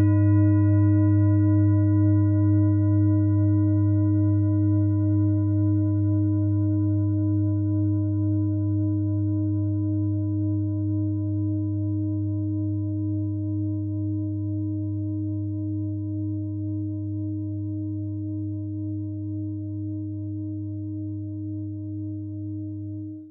Klangschale Bengalen Nr.28
Sie ist neu und wurde gezielt nach altem 7-Metalle-Rezept in Handarbeit gezogen und gehämmert.
(Ermittelt mit dem Filzklöppel oder Gummikernschlegel)
Die Frequenz des Sonnentons liegt bei 126,2 Hz und dessen tieferen und höheren Oktaven. In unserer Tonleiter ist das nahe beim "H".